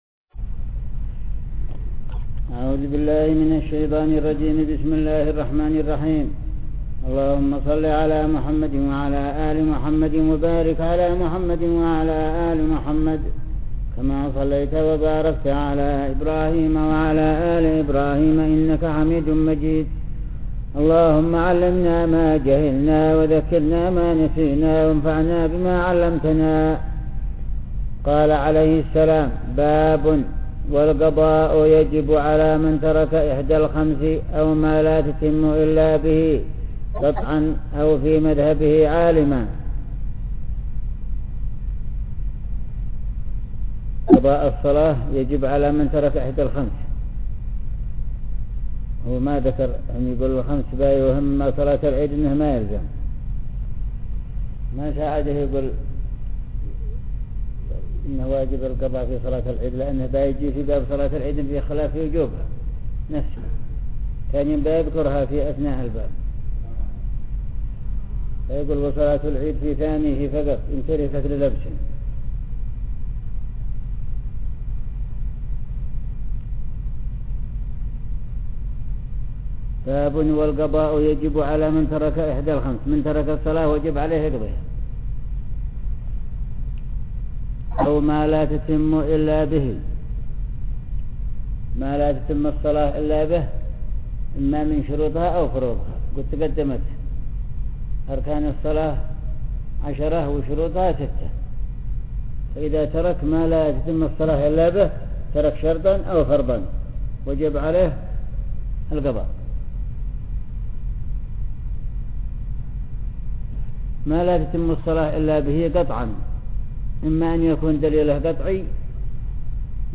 الاصل المصلح      انظر الدقيقة   12,38        عند ذكر البريد [Low quality].mp3